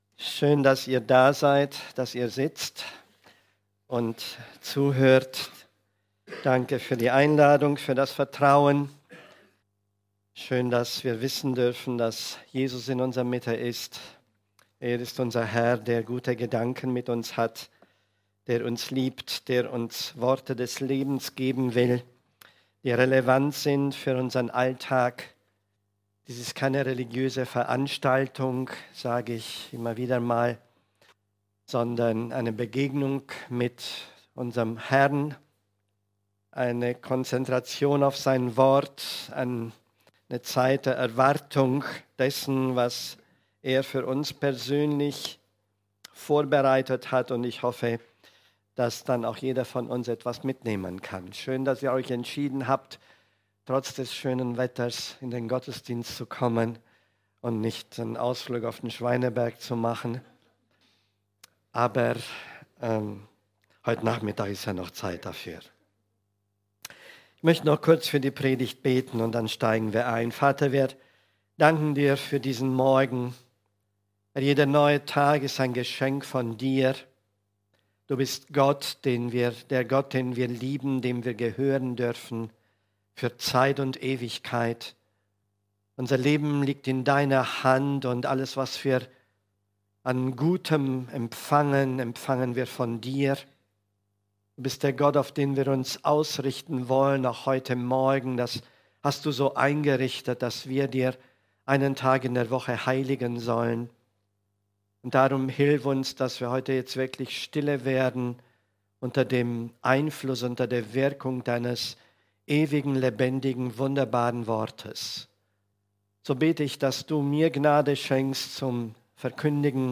PREDIGTEN zum Nachhören als Downloadliste – FEZ